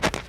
snow-02.ogg